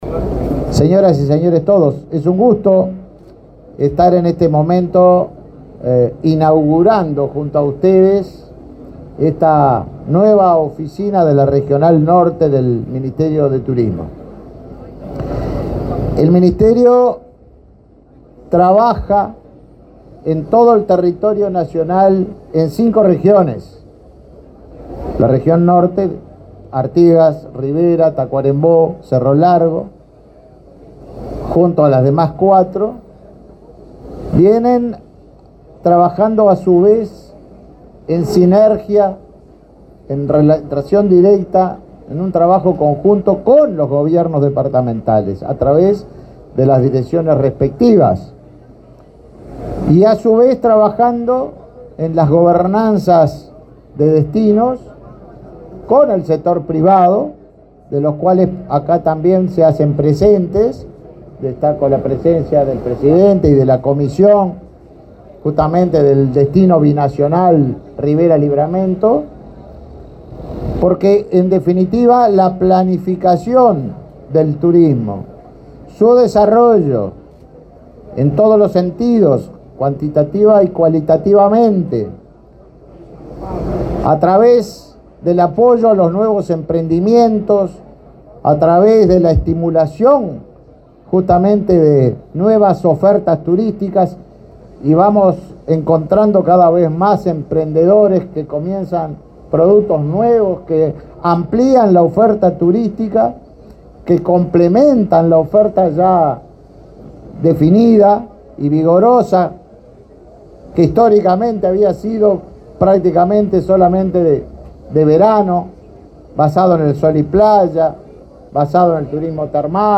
Palabras del ministro de Turismo, Tabaré Viera
El ministro de Turismo, Tabaré Viera, inauguró en Rivera la oficina de la región norte, que abarca la gestión territorial de los departamentos de